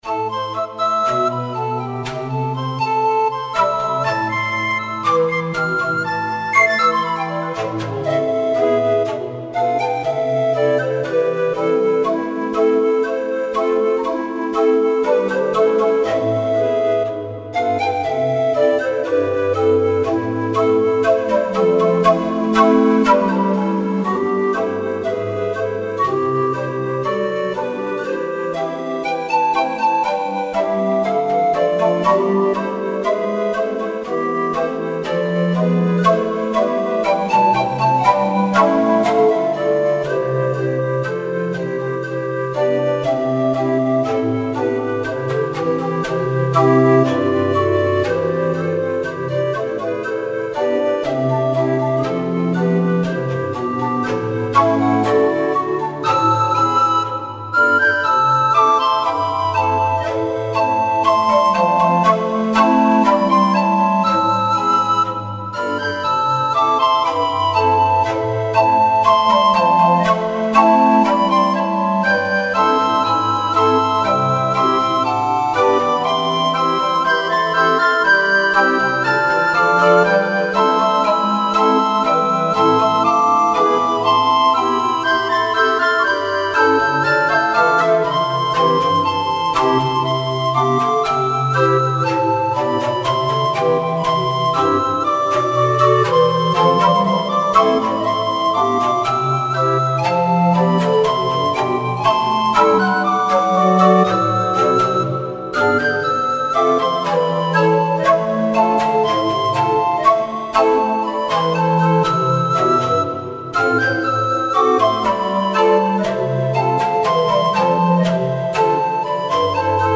Bis das fertig ist, gibt es hier aber erst noch die fast unvermeidliche Neufassung für Dampforgel.
Ael Fedhrins ‚Censorical Rondeau‘ für Dampforgel